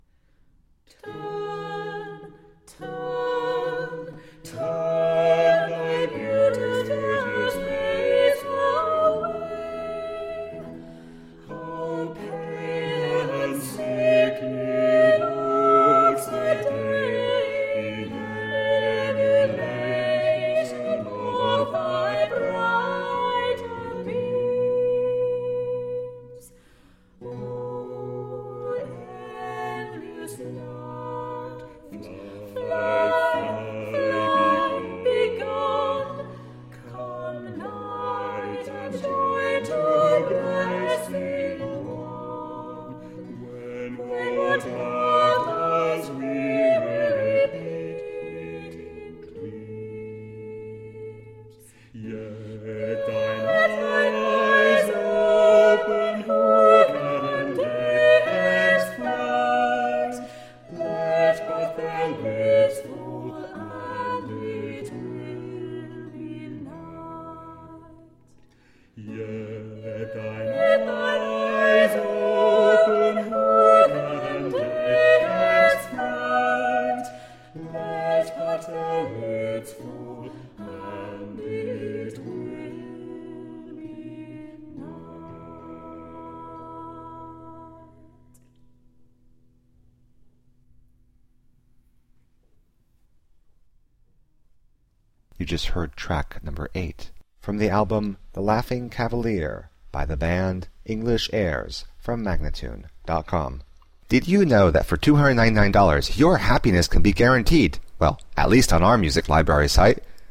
Traditional early english music.